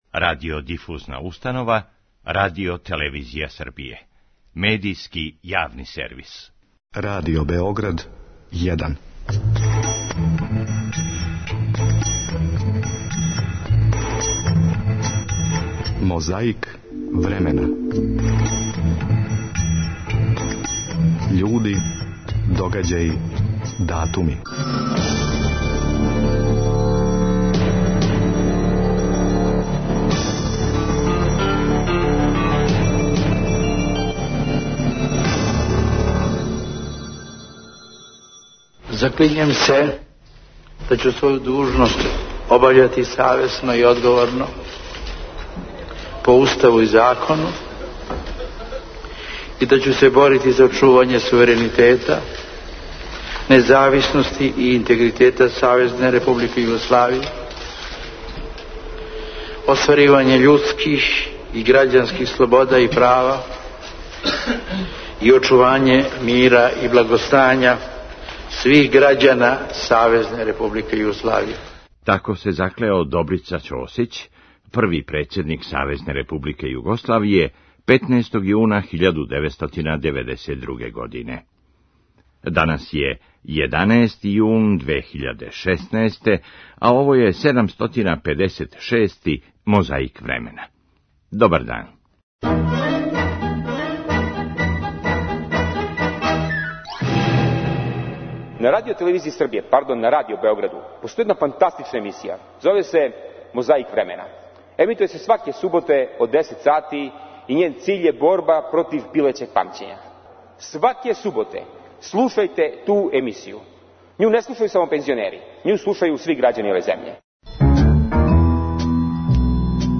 Подсећа на прошлост (културну, историјску, политичку, спортску и сваку другу) уз помоћ материјала из Тонског архива, Документације и библиотеке Радио Београда.
Председник Српског покрета обнове Вук Драшковић говорио је на Равној Гори 13. јуна 1993. године.